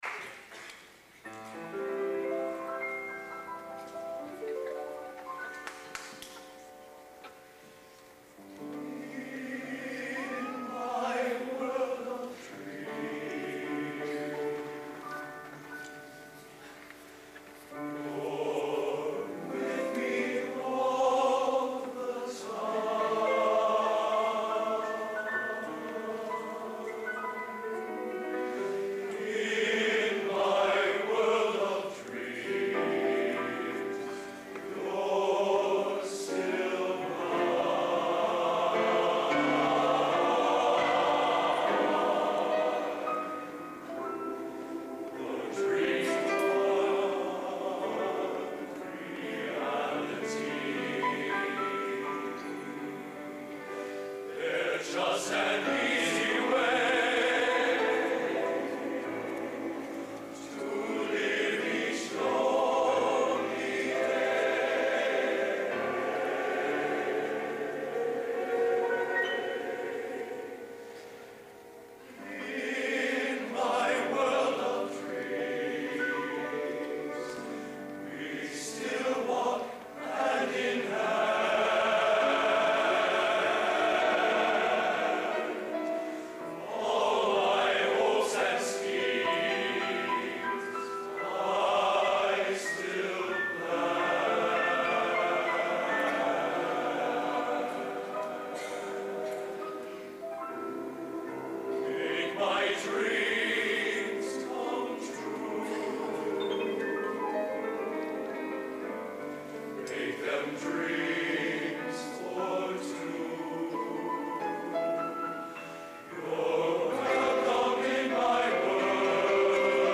Location: Purdue Memorial Union, West Lafayette, Indiana
Genre: Popular / Standards | Type: